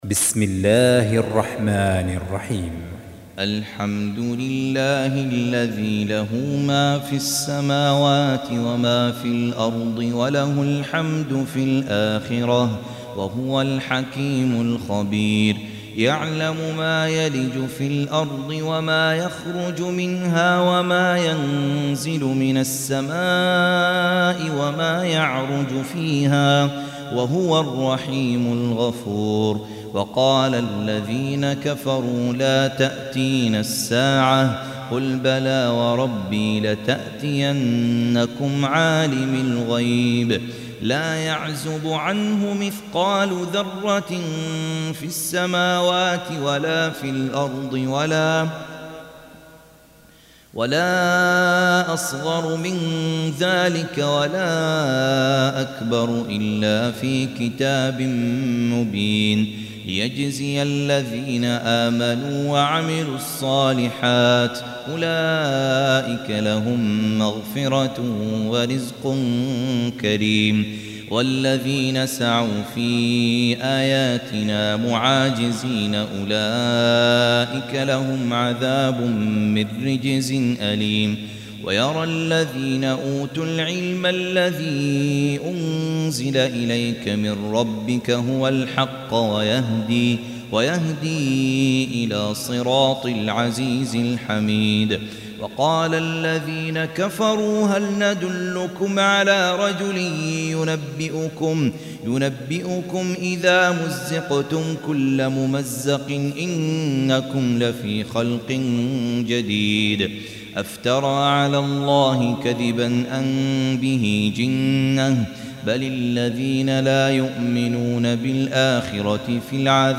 Surah Repeating تكرار السورة Download Surah حمّل السورة Reciting Murattalah Audio for 34. Surah Saba' سورة سبأ N.B *Surah Includes Al-Basmalah Reciters Sequents تتابع التلاوات Reciters Repeats تكرار التلاوات